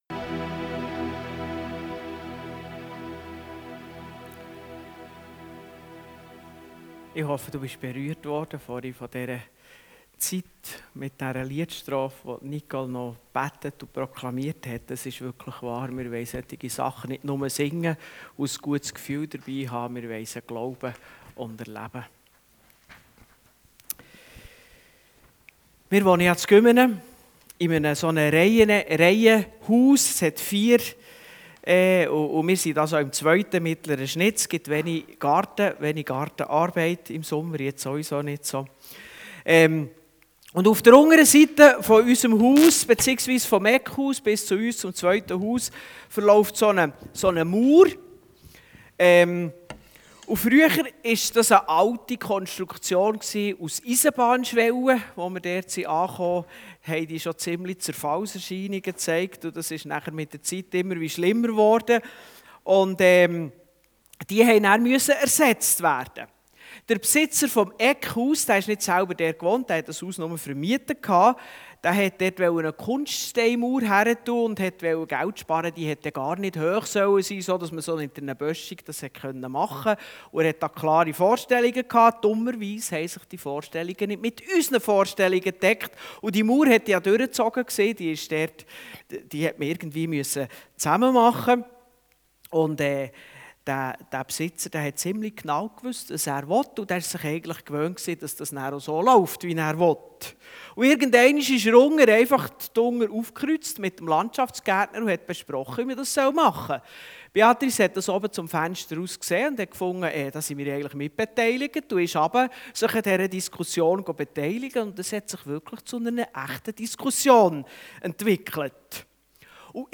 Nehmen & Geben Dienstart: Gottesdienst Themen